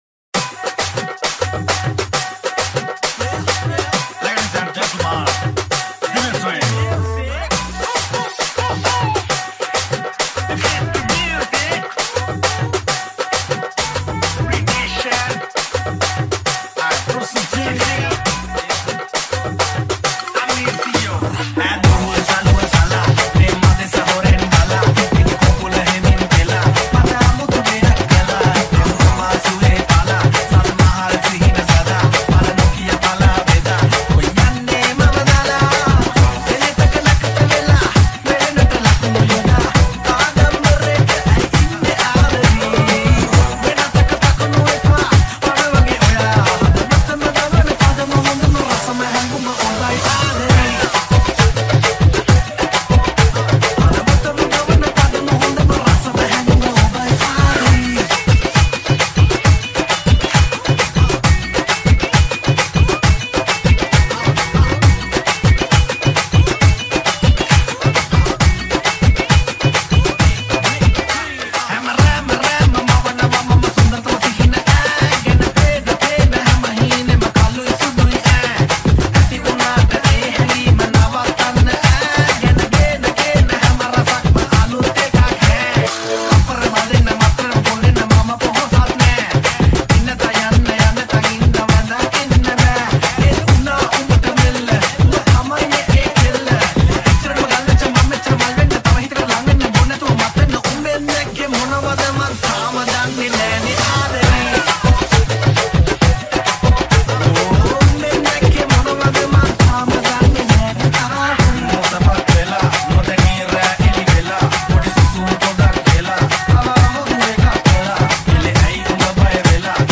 Sinhala Remix 2020